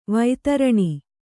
♪ vaitaraṇi